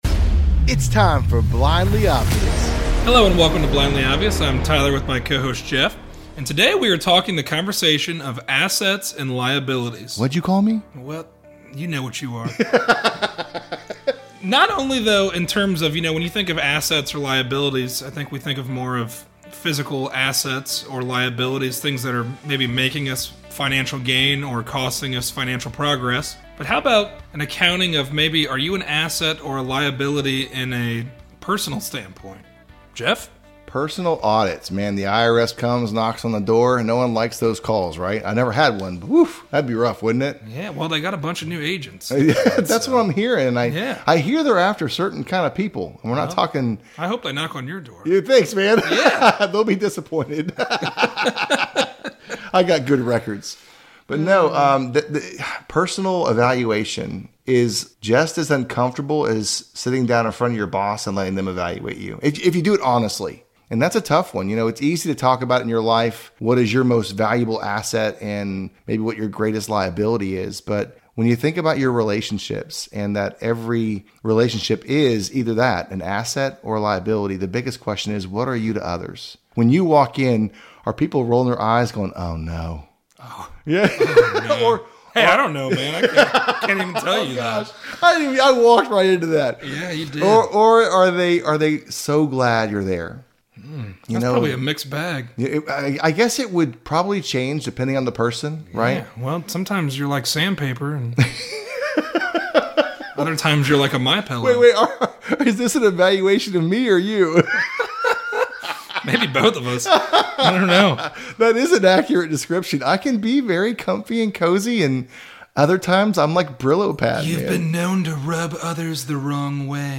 A conversation on assets and liabilities. We all understand these terms in the physical sense, but what about in the intangible sense? Are we more of an asset or a liability when it comes to others?